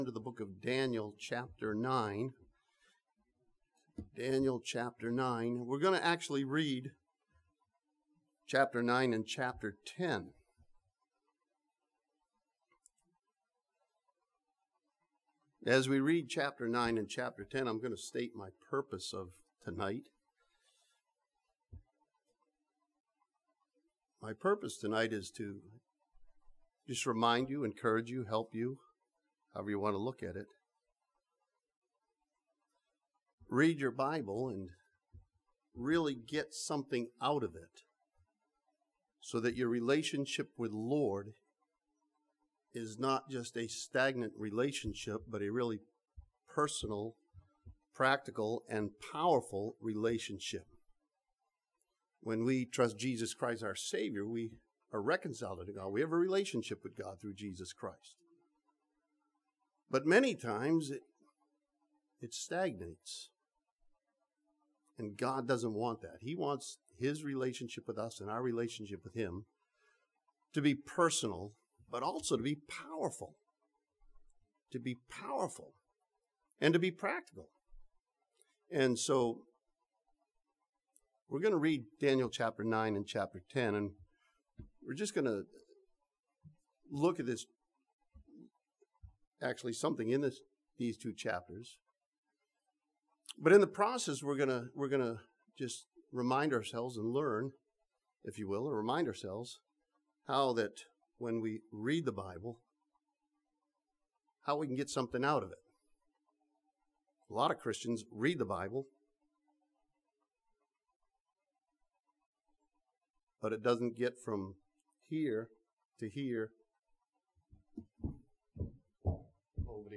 This sermon from Daniel chapter 9 challenges believers to read their Bible and to relate the Bible to their lives.